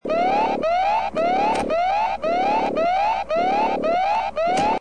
SFX紧急触发机关拉响防空警报音效下载
SFX音效